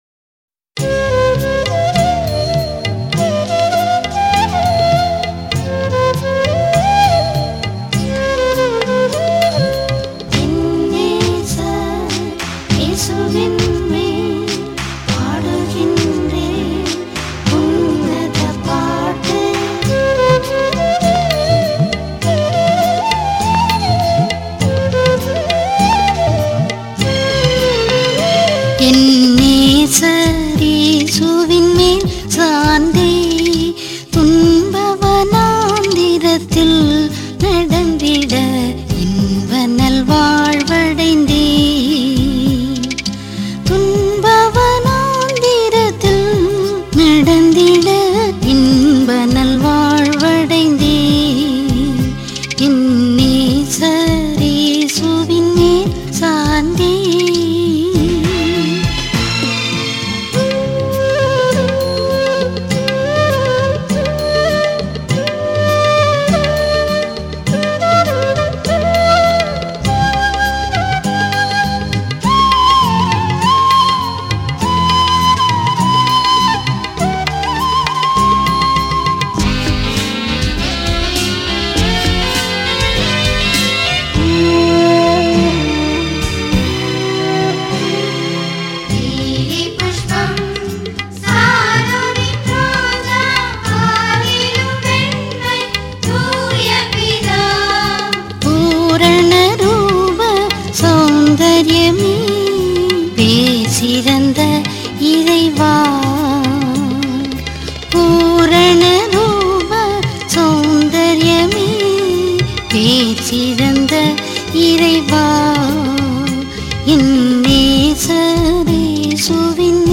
gospel album
Royalty-free Christian music available for free download.